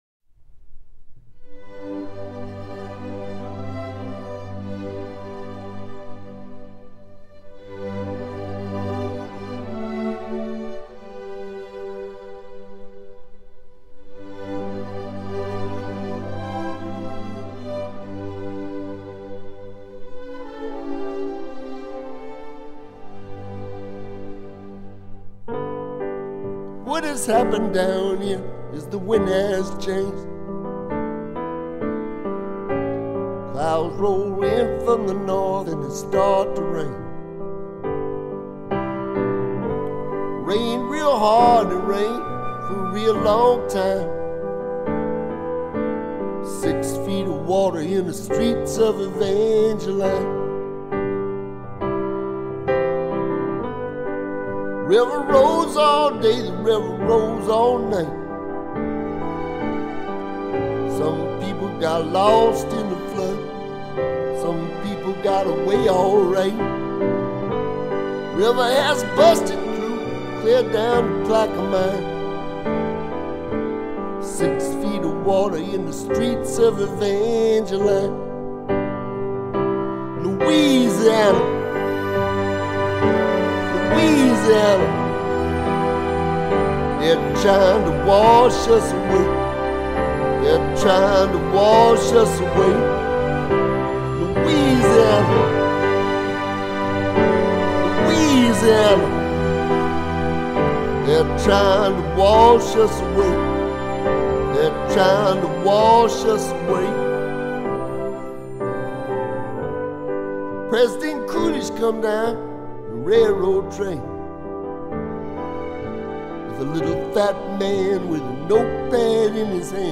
slow dance songs